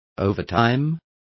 Complete with pronunciation of the translation of overtimes.